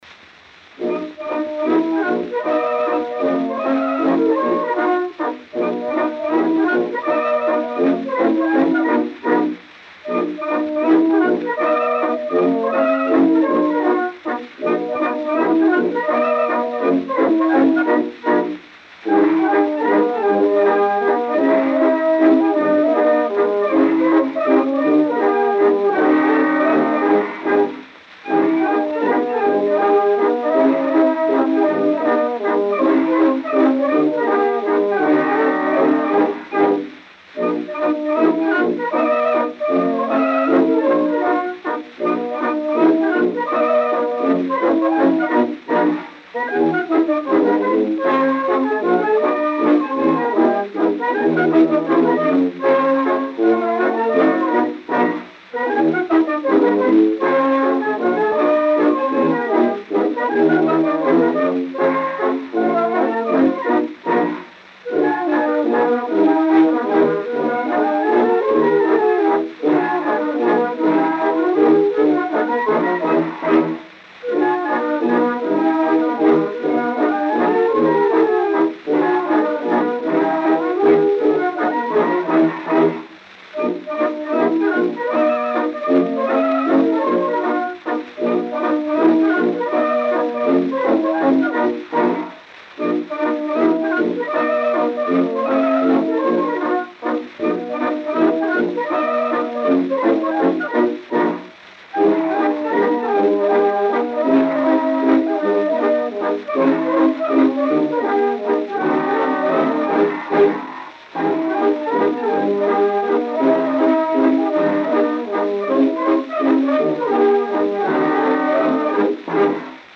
O gênero musical foi descrito como "Mazurka".